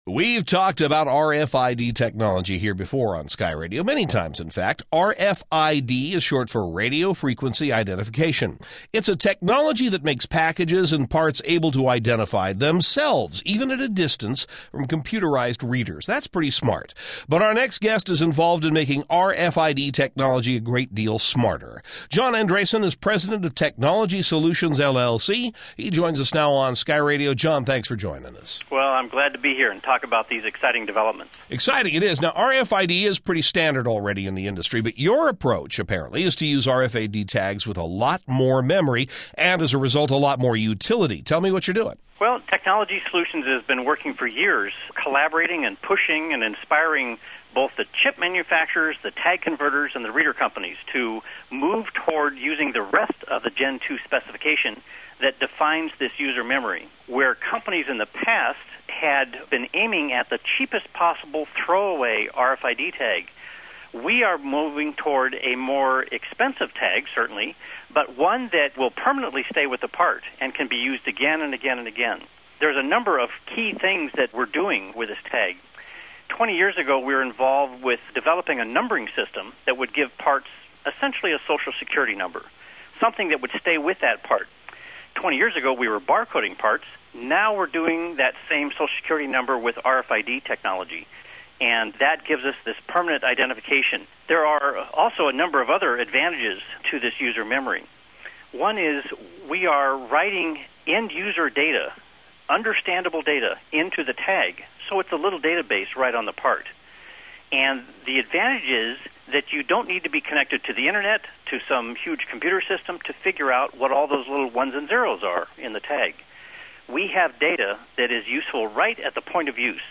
Interview: SkyRadio Talks RFID with American Airlines
Sky-Radio-Interview.mp3